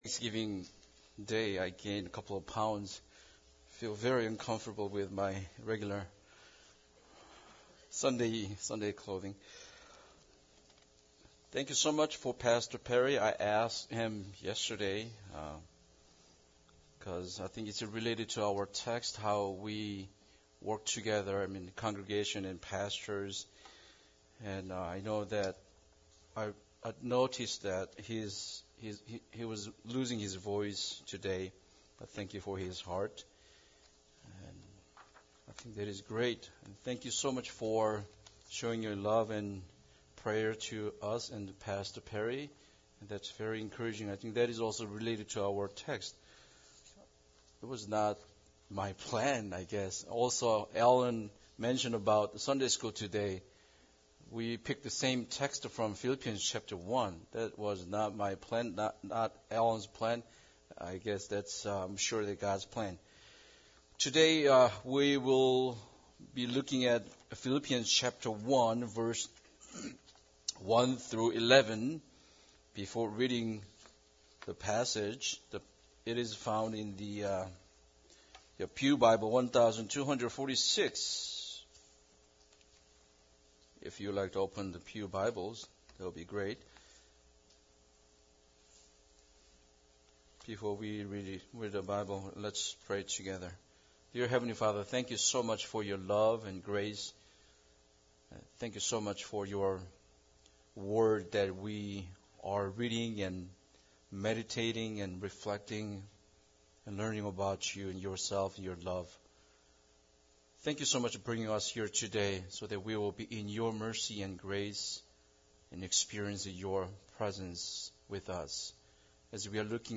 Philippians 1:1-11 Service Type: Sunday Service Bible Text